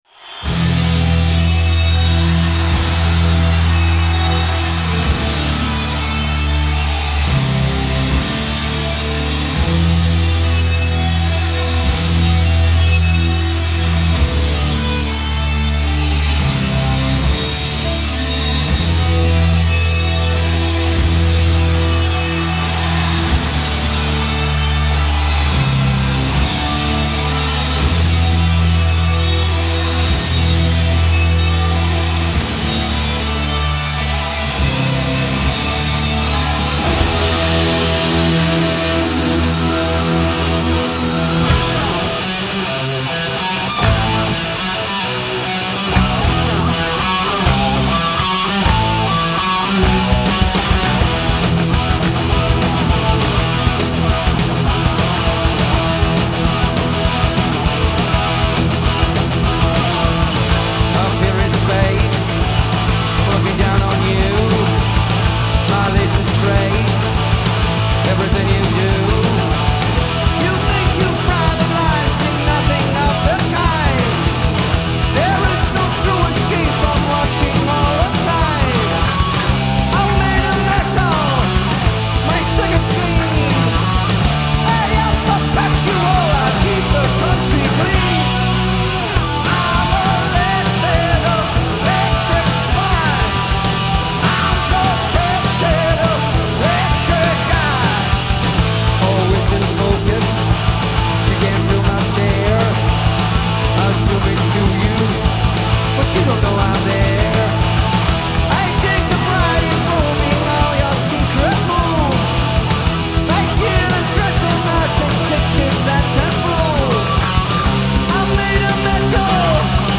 Genre : Metal
Live at The Summit, Houston, 1986